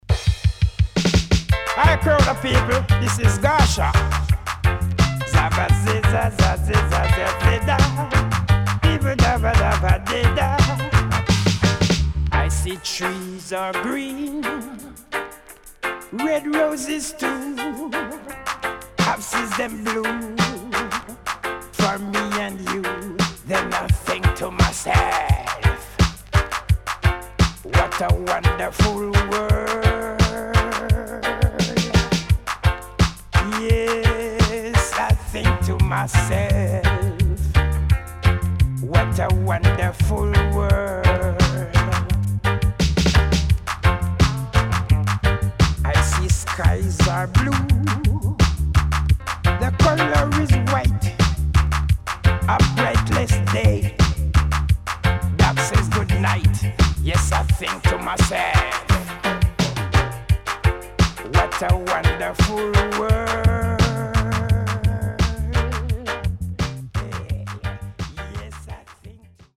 Dancehall Cover